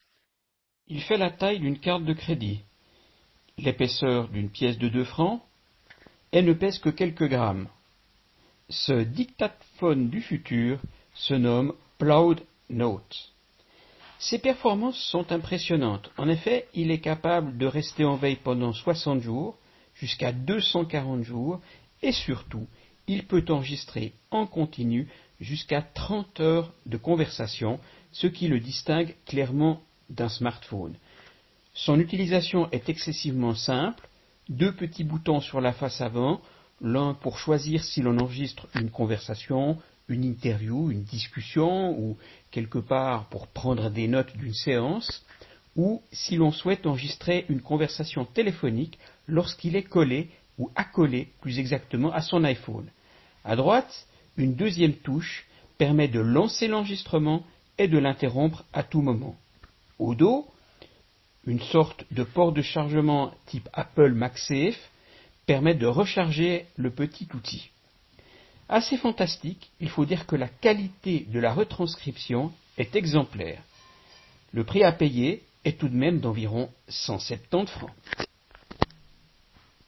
11-17_Reunion_Ploud_Note_-_Caracteristiques_et_Strategies.mp3